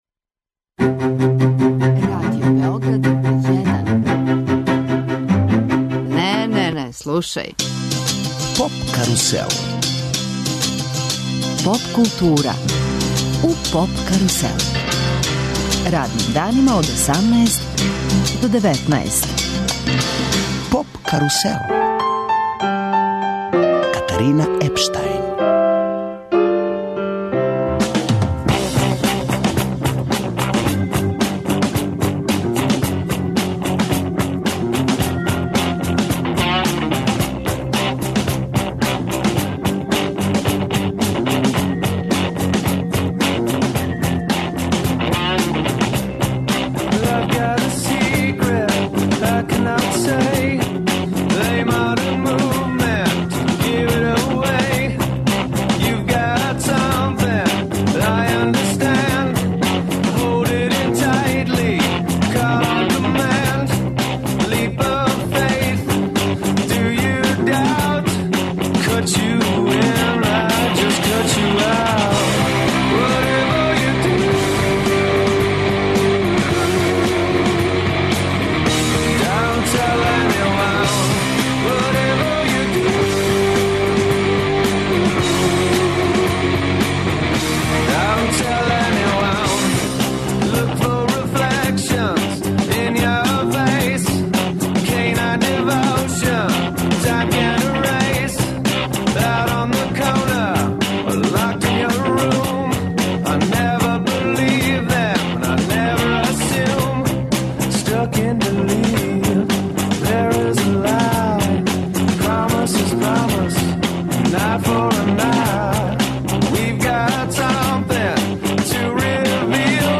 Емисија се реализује са Петроварадинске тврђаве...